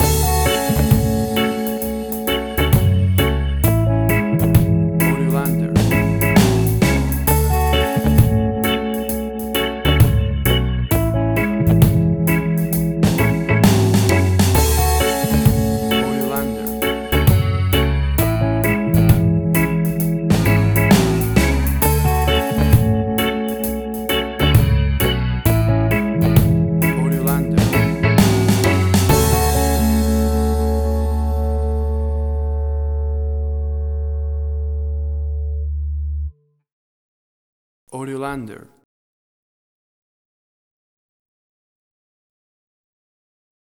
A chilled and relaxed piece of smooth reggae music!
Tempo (BPM): 66